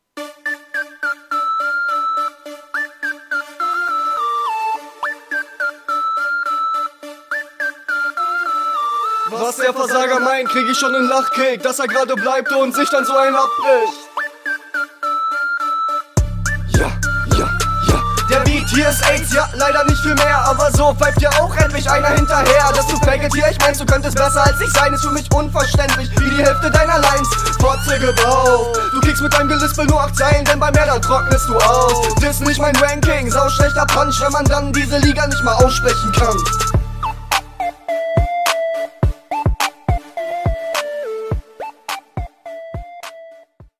deine Stimme könnte noch etwas lauter und die Doubles an manchen Stellen etwas sauberer.
Flow etwa auf einem Level mit deinem Gegner, geht nach vorne, ist weitestgehend taktsicher und …